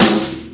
• '00s Rock Acoustic Snare A Key 05.wav
Royality free snare drum sound tuned to the A note. Loudest frequency: 1964Hz
00s-rock-acoustic-snare-a-key-05-VJ6.wav